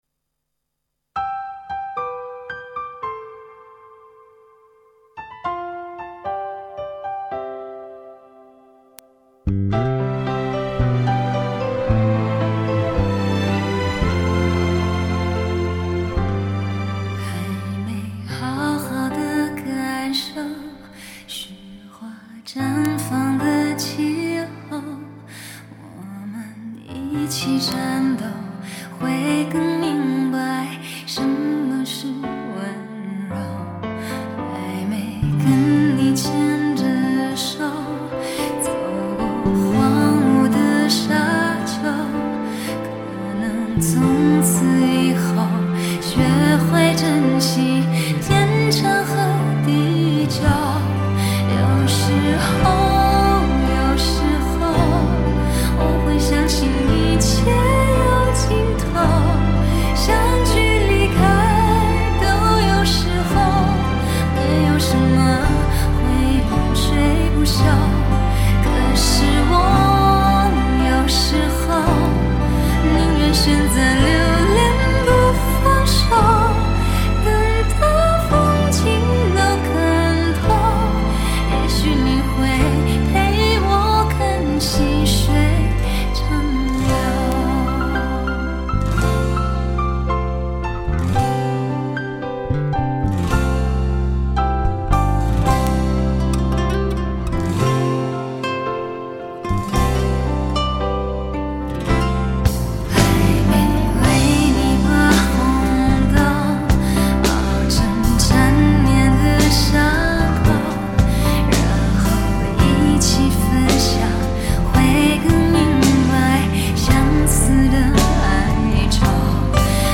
无损音质原人原唱，经典！值得聆听永久珍藏